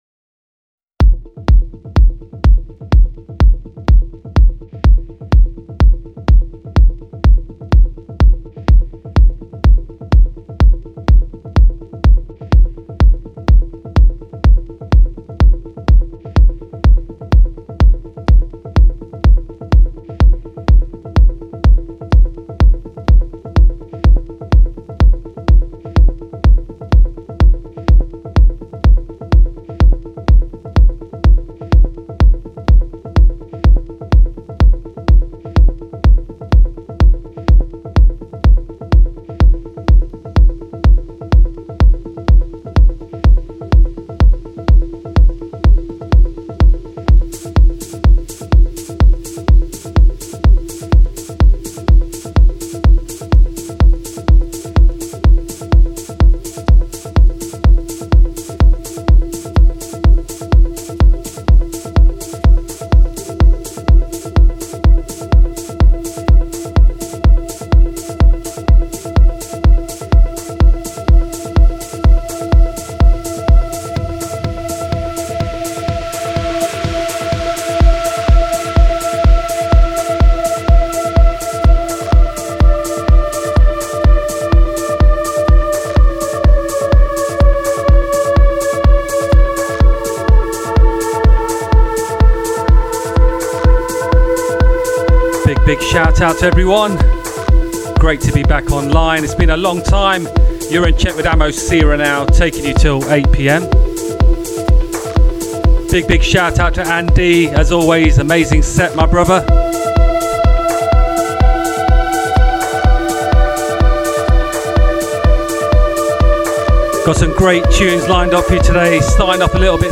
Melodic and Deep Techno